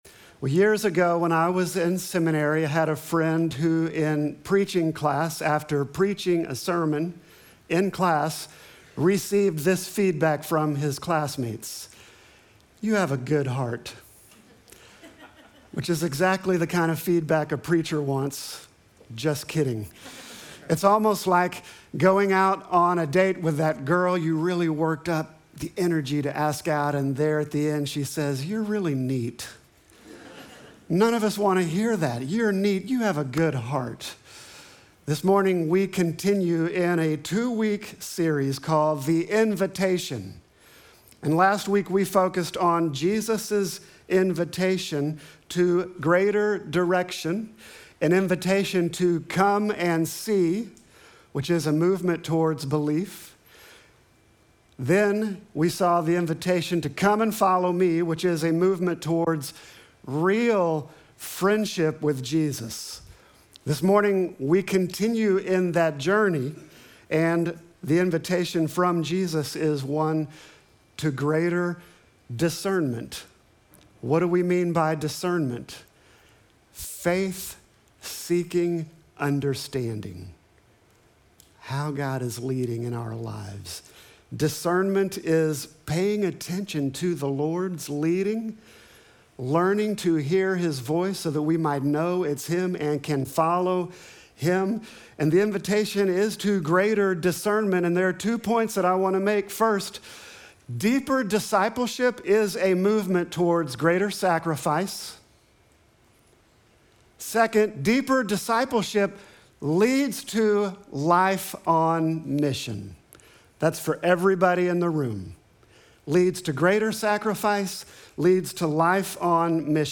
Sermon text: Mark 3:13-15